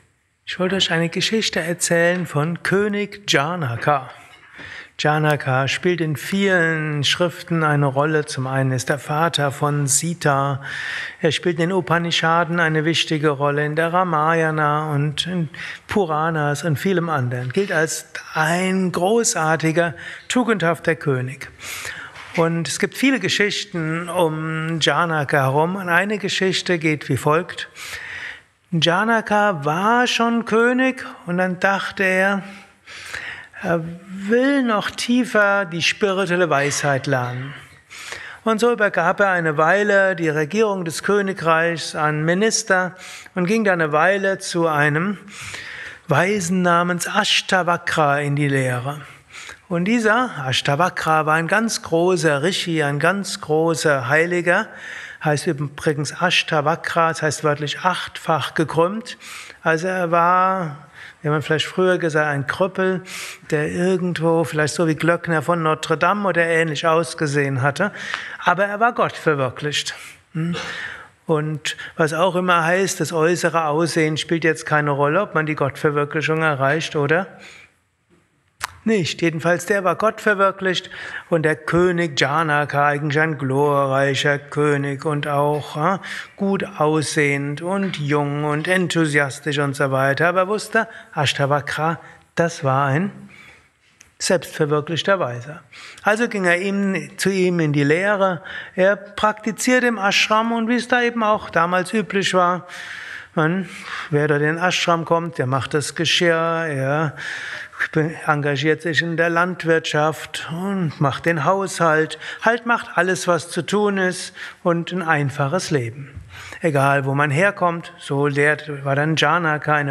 gehalten nach einer Meditation im Yoga Vidya Ashram Bad Meinberg.